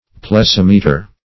Plessimeter \Ples*sim"e*ter\, n.